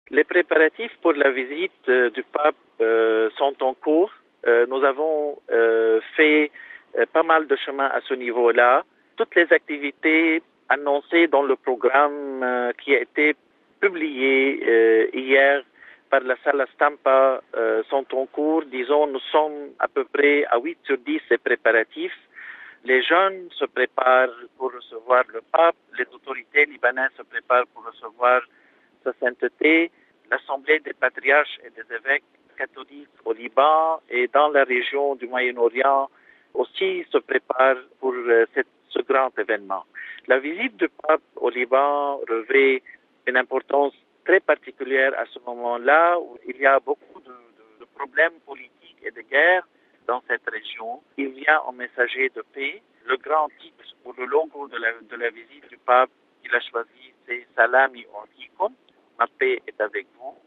MP3 Des propos recueillis par notre confrère du programme italien